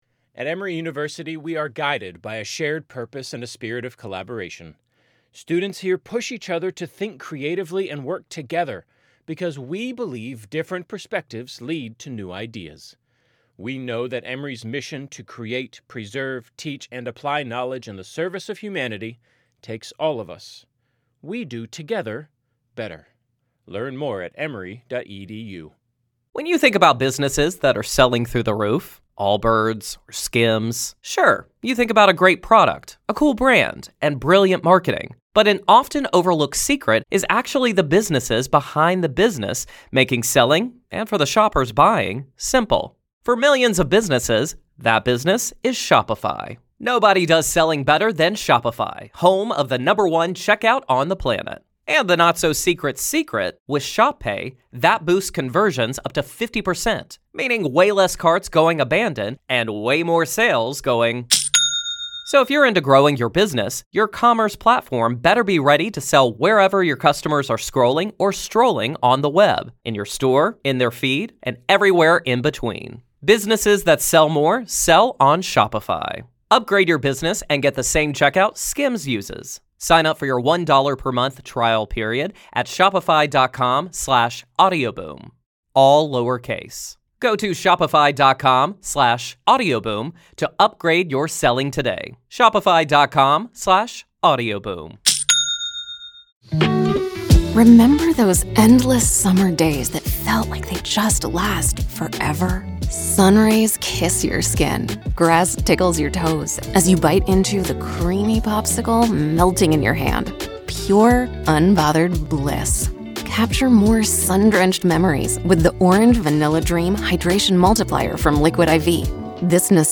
True Crime Today | Daily True Crime News & Interviews / Should The Commonwealth Try The Karen Read Case Again?